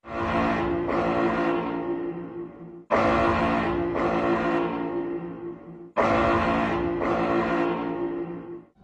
WULA_MotherShip_Planet_Interdiction_IncomingSound.wav